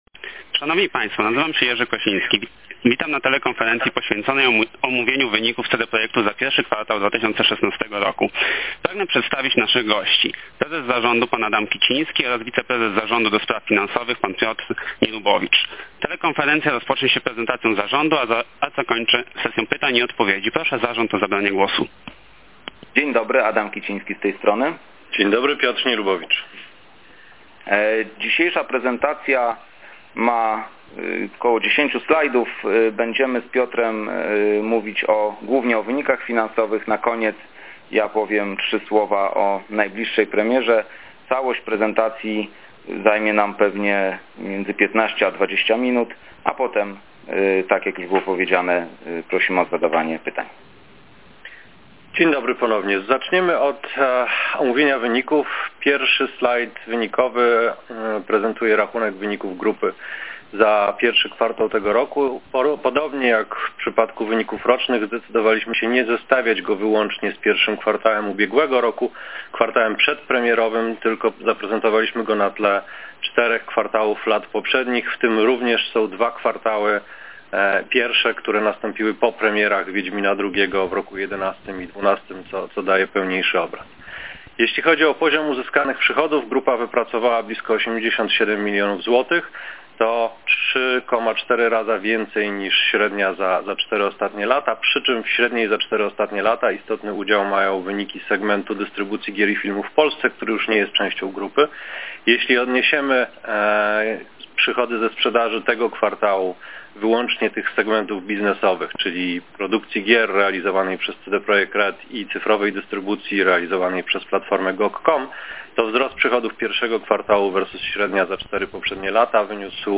Zapis telekonferencji wynikowej za Q1 2016